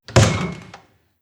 Abrir la puerta de un aparador 02
Sonidos: Acciones humanas
Sonidos: Hogar